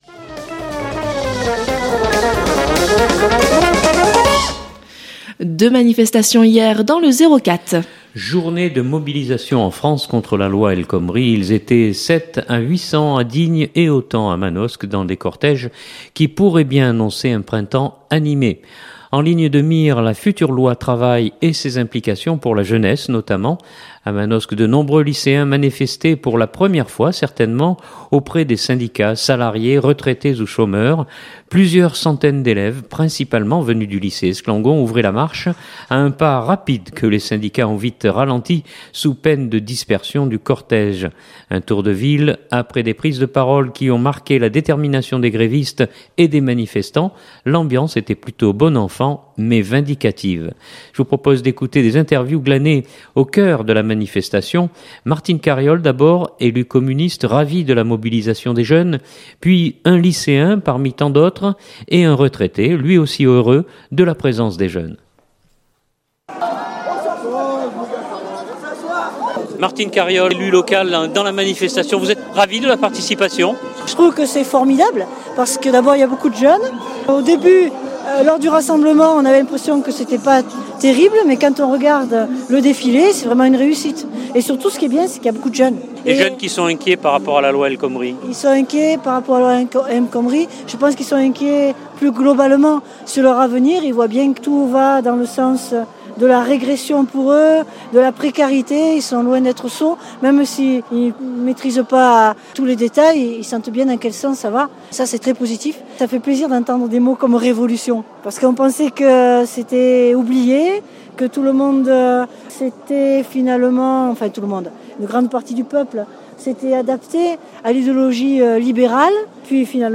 Manifestation manosque.mp3 (3.17 Mo)
Un tour de ville après des prises de parole qui ont marqué la détermination des grévistes et des manifestants. L’ambiance était plutôt bon enfant mais vindicative.